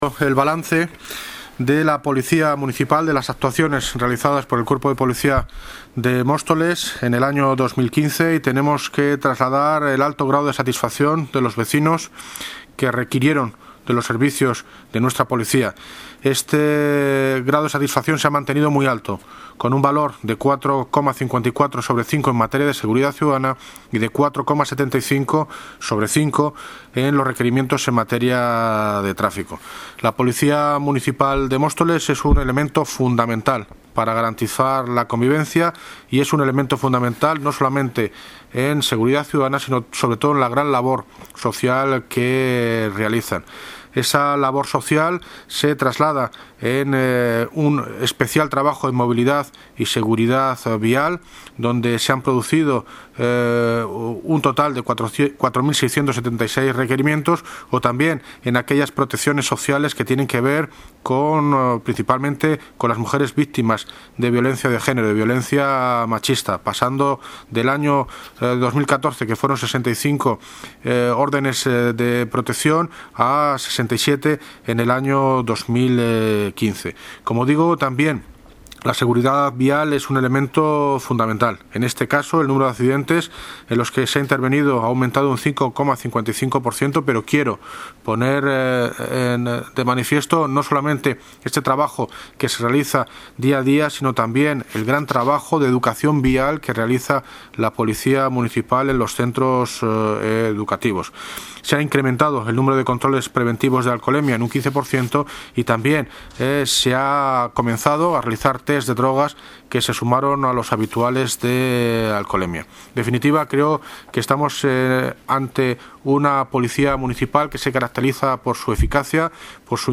Audio - David Lucas (Alcalde de Móstoles) Sobre Balance de la Policia Municipal